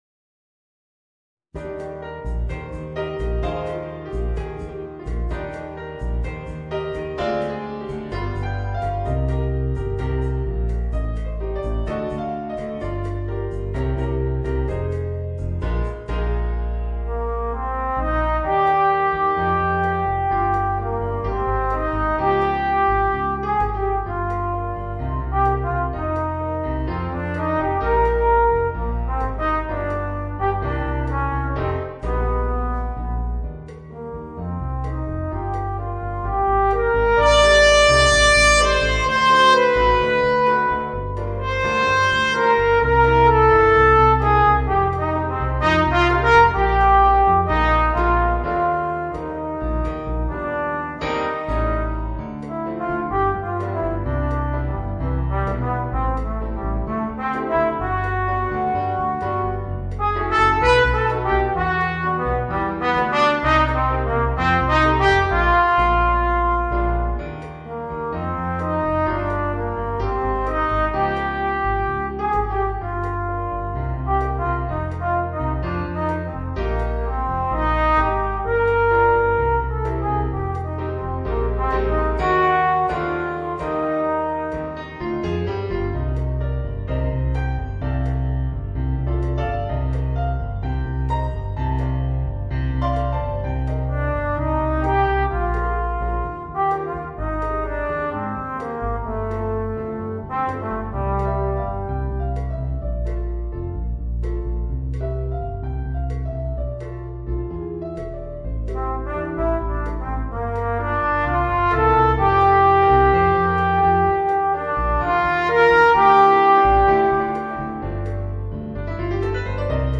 Trombone & Piano (Drums & Bass Guitar optional)